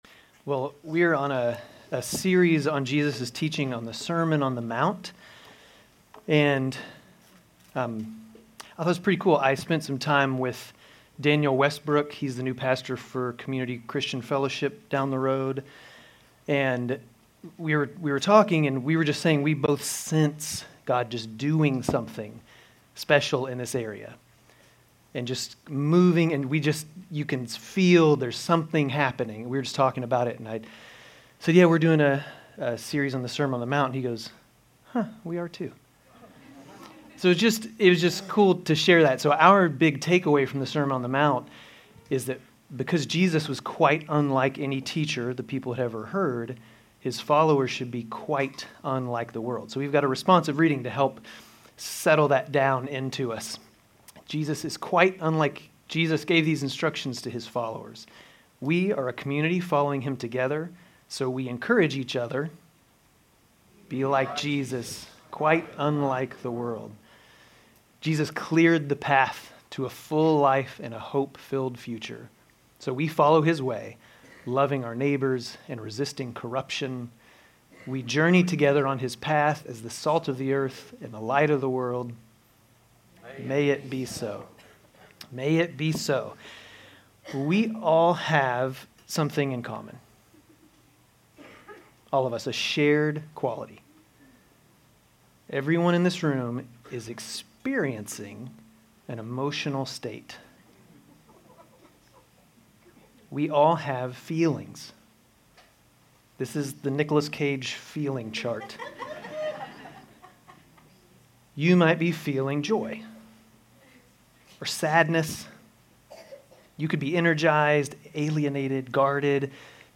Grace Community Church Dover Campus Sermons 2_16 Dover Campus Feb 23 2025 | 00:25:17 1x 00:00 / 00:25:17 Subscribe Share RSS Feed Share Link Embed